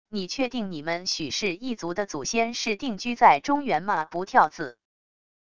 你确定你们许氏一族的祖先是定居在中原吗不跳字wav音频生成系统WAV Audio Player